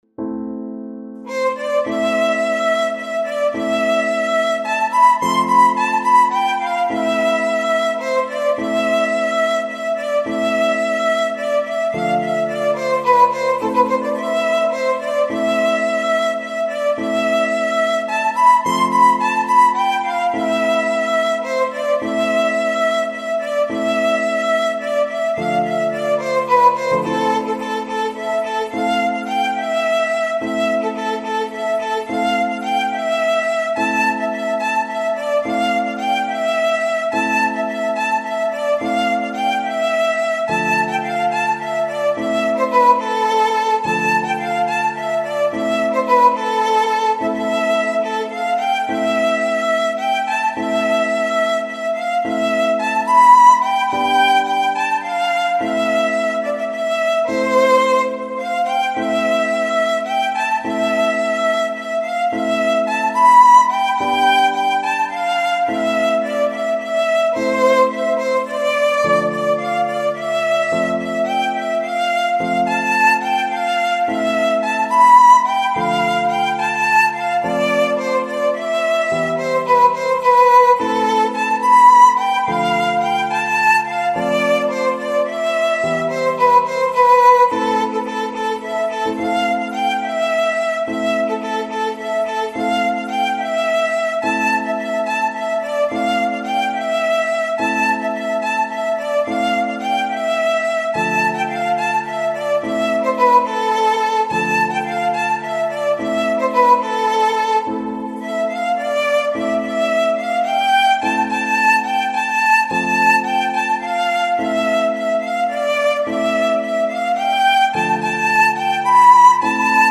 ساز : ویولون